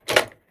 gear_rattle_weap_launcher_02.ogg